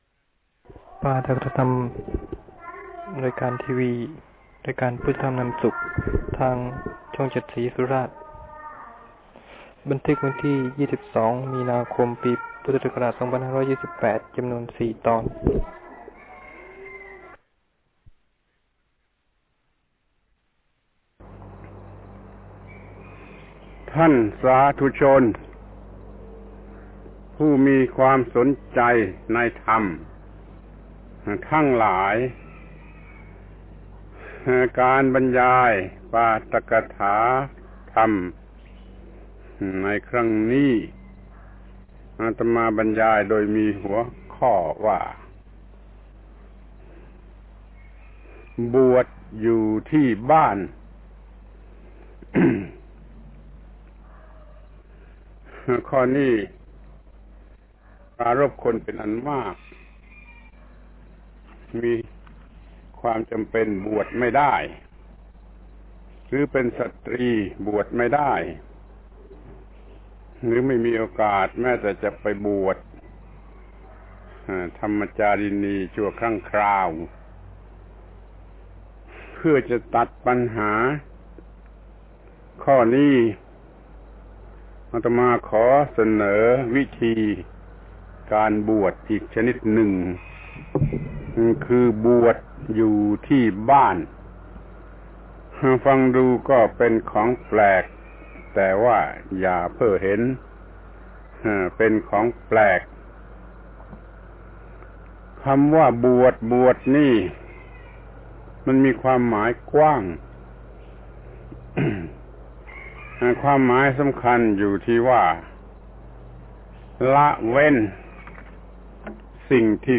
พระธรรมโกศาจารย์ (พุทธทาสภิกขุ) - ปาฐกถาธรรมทางโทรทัศน์รายการพุทธธรรมนำสุข ชุดบวชอยู่ที่บ้าน (มี ๔ ตอน) ครั้ง ๖๘ บวชอยู่ที่บ้านโดยยึดหลักพละห้า หรืออินทรีย์ห้า